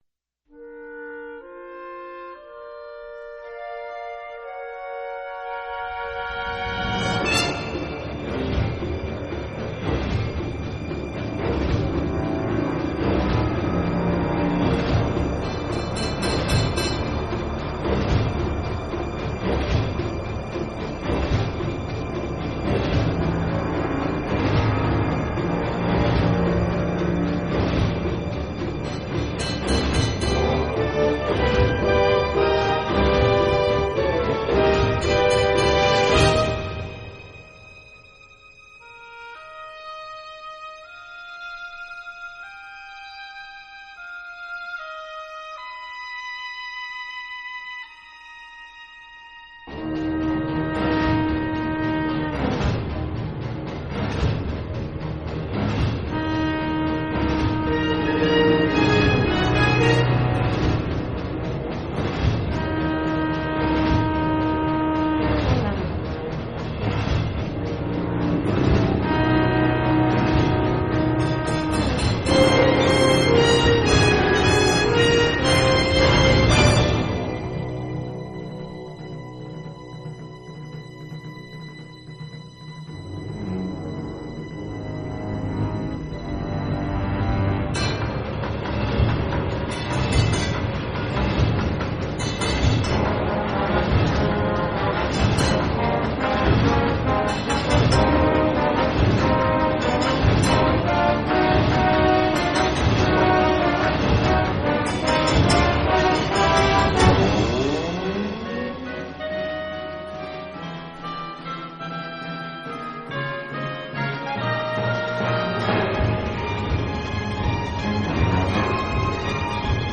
Soundtrack, Orchestral